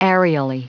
Prononciation du mot aerially en anglais (fichier audio)
Prononciation du mot : aerially